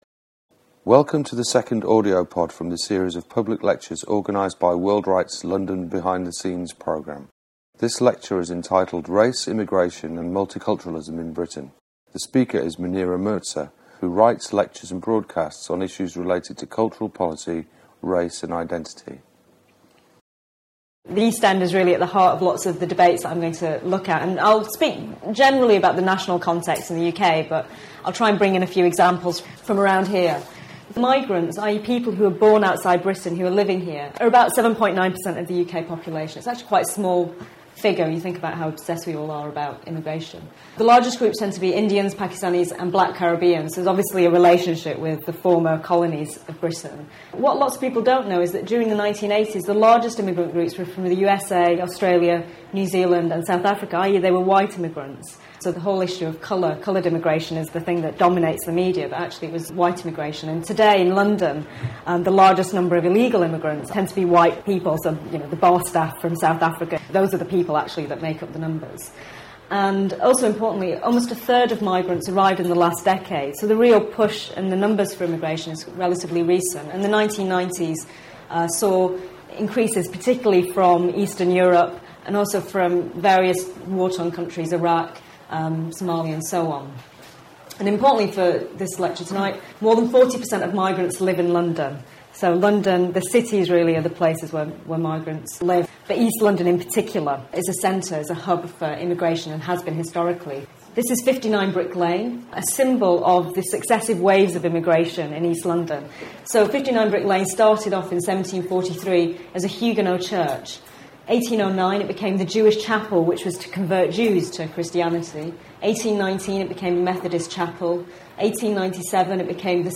Public Lectures: Immigration, identity and multiculturalism
Public Lectures: Immigration, identity and multiculturalism Held on: Wednesday 2nd April, 7-9:30pm Speaker: Munira Mirza How have attitudes to immigration changed historically? What does modern multiculturalism as policy and lived experience offer us today: a harmonious East End or a divisive focus on difference?